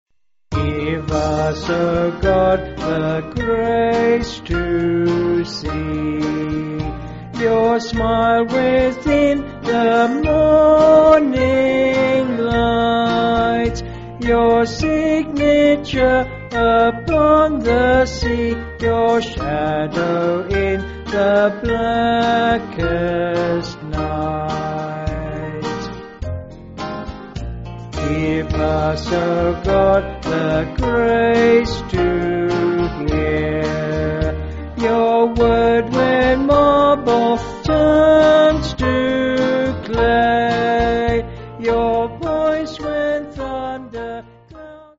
Hymn books
(BH)   4/Eb
Vocals and Band